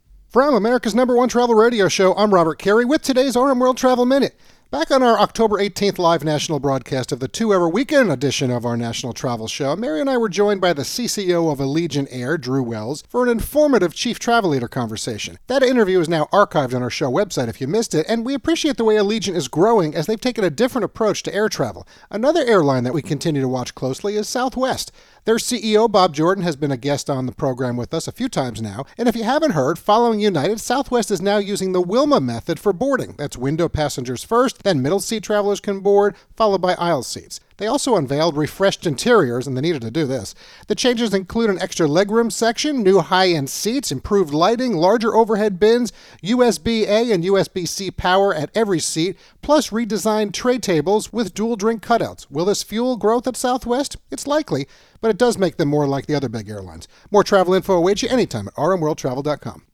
America's #1 Travel Radio Show